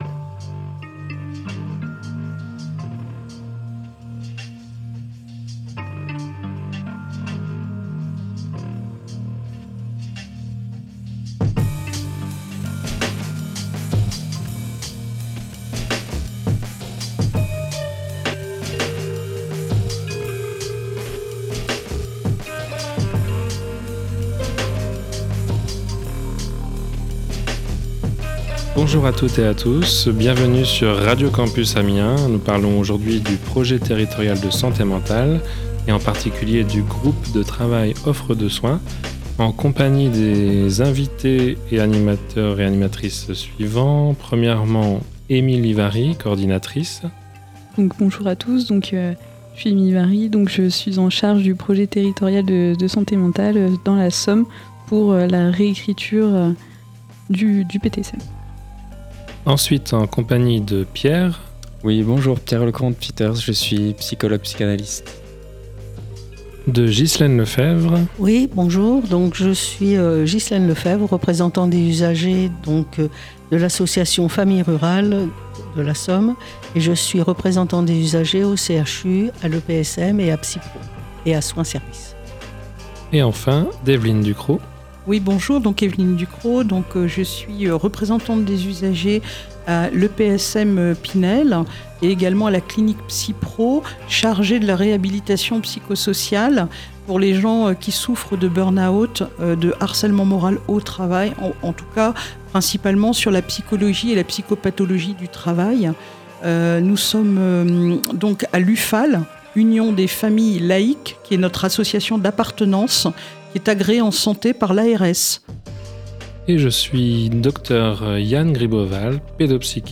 Une émission consacrée aux liens en santé mentale : ceux qui soutiennent, ceux qui fragilisent, ceux qu’il est possible de retisser.